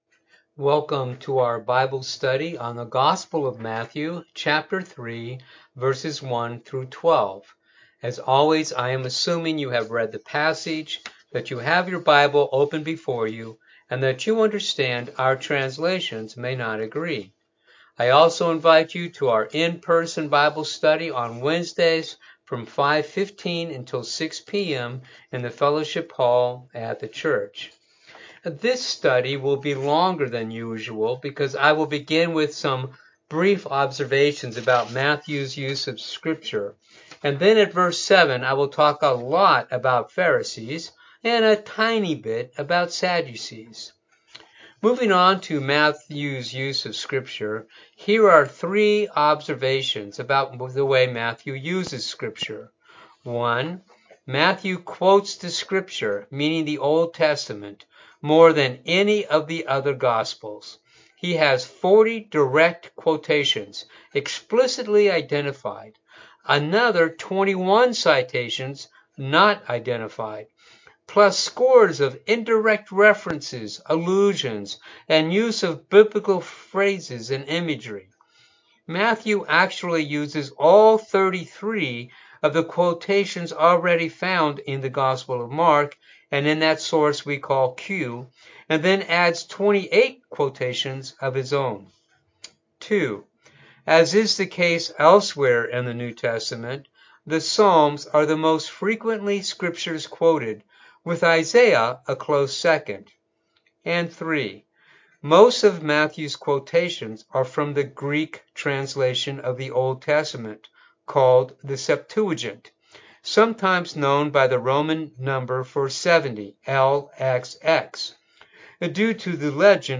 Bible Study for the December 4 Service